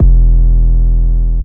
808s
MURDA_808_GOYARD_E.wav